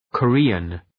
{kə’ri:ən}
Korean.mp3